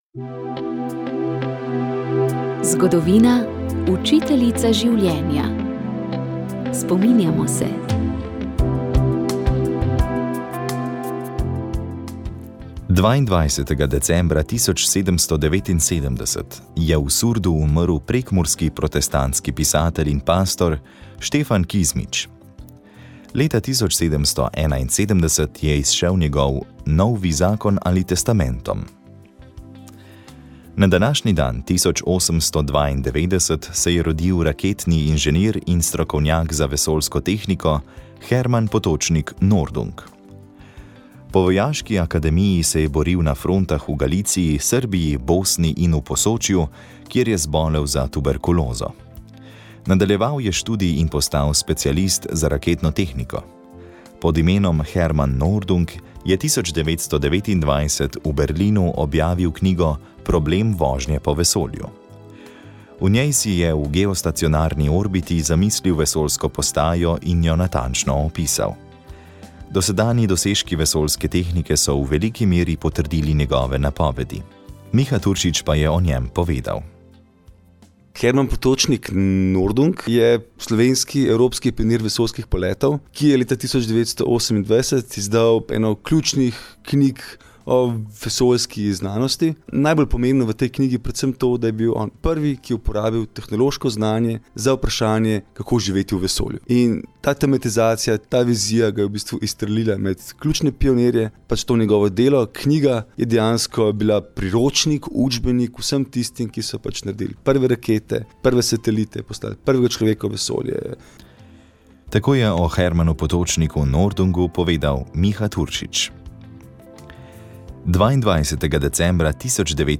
Za nami je nedelja Svetega pisma in v tokratni oddaji Sol in luč smo poslušali nekaj odlomkov iz knjige z naslovom Bistvenih sto v kateri avtor, Whitney Kuniholm z izrazito sodobnim, ter mestoma tudi humornim pristopom odkriva ozadja odlomkov, ki so aktualni v vseh časih.